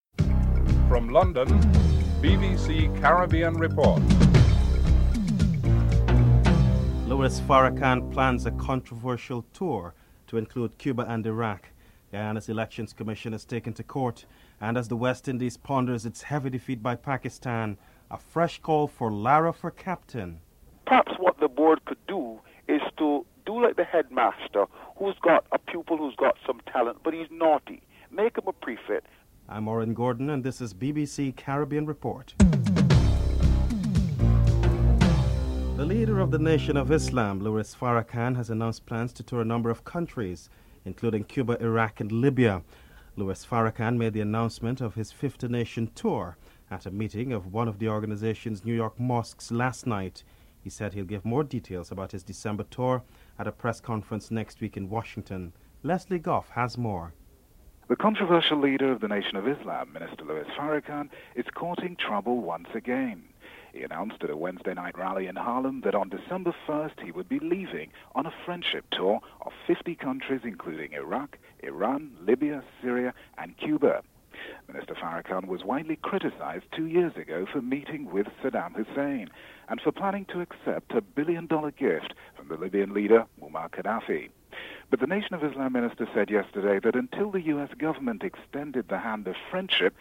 1. Headlines (00:00-00:35)
Tourism Minister Billie Miller is interviewed (09:08-10:59)